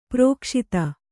♪ prōkṣita